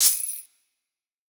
TS Perc_4.wav